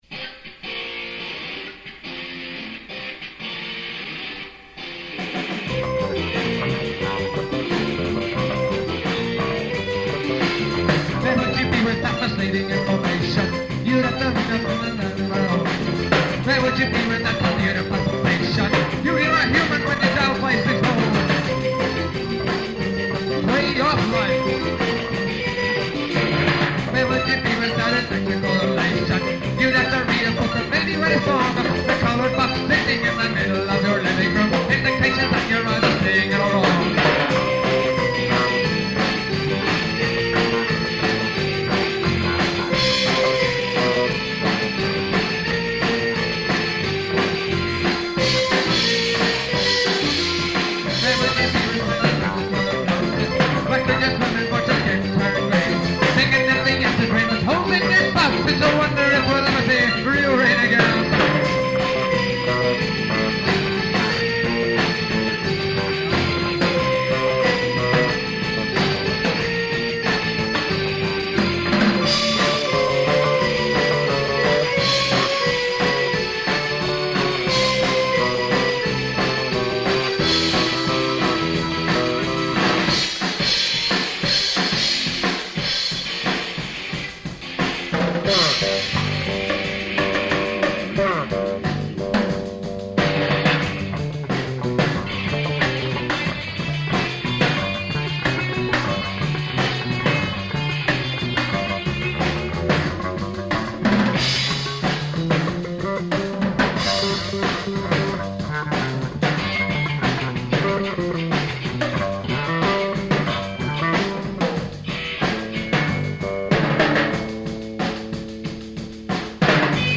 Keep in mind that these are from 20-year old cassette tapes, so sound quailty is definitely an issue. We apologize in advance for all the mistakes and questionable singing, but this was a practice session after all.
The band played some covers and mostly original material in the style of ska and reggae.